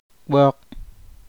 [HH] verb, transitive peel with hand or knife, unlock